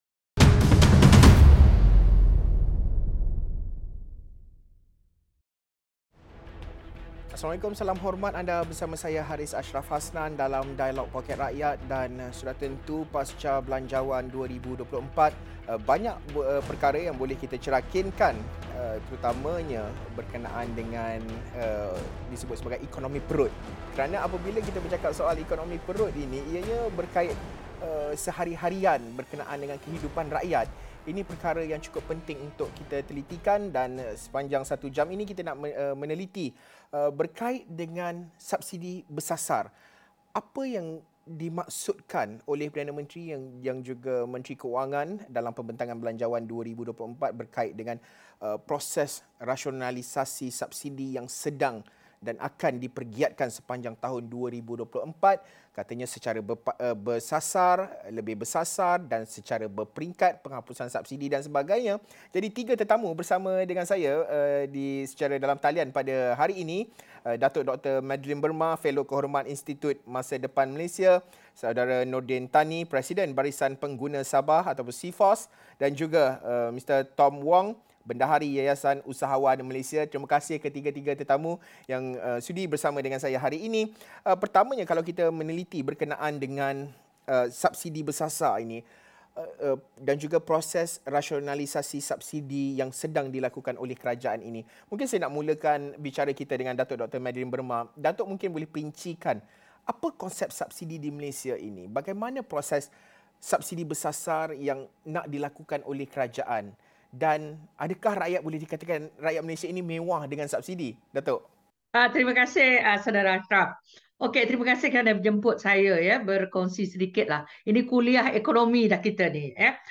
Apakah cara dan kaedah subsidi bersasar yang bakal diperkenalkan? Sejauhmana rakyat memahami pendekatan apungan harga dan mendapat manfaat? Diskusi dan analisis Dialog Poket Rakyat jam 8:30 malam ini.